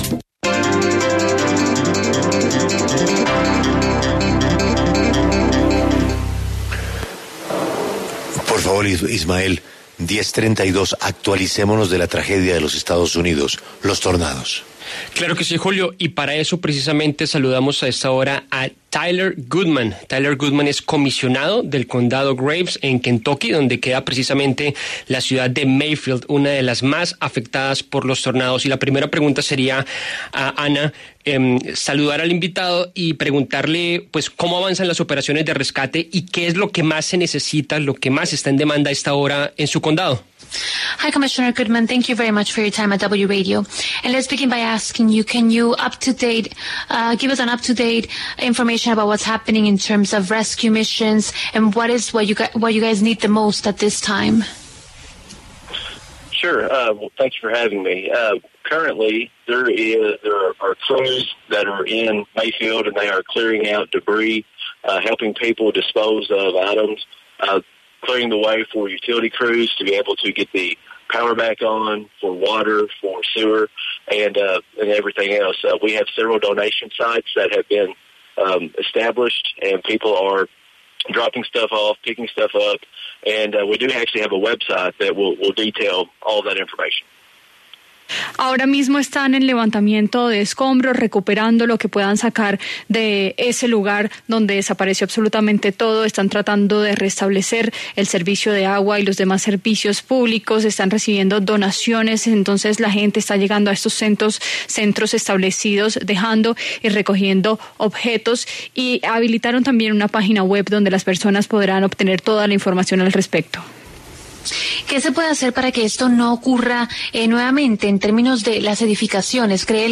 Tyler Goodman, comisionado del condado de Graves, Kentucky, habló en La W sobre el panorama en la región tras el paso de los tornados que dejó al menos 83 muertos.
En el encabezado escuche la entrevista completa con Tyler Goodman, comisionado del condado de Graves, Kentucky.